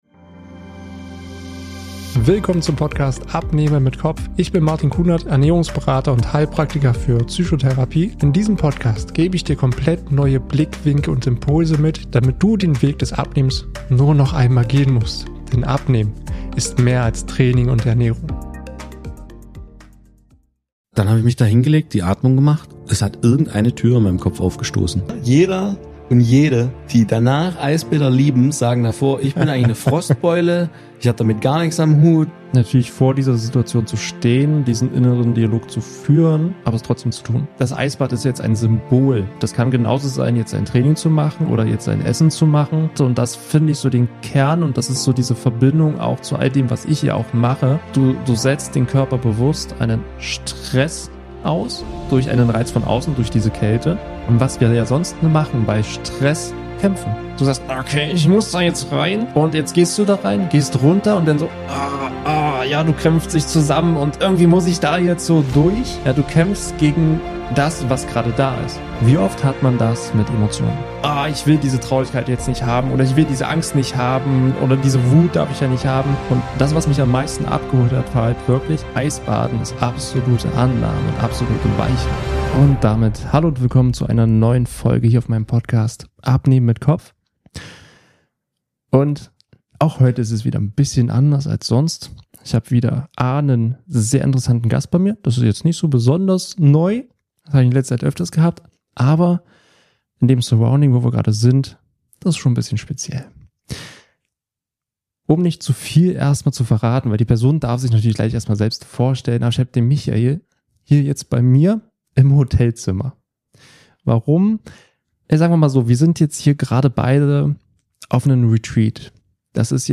In meinem Interview-Format schaue ich gemeinsam mit meinem Gast weit über den Tellerrand des Abnehmens hinaus.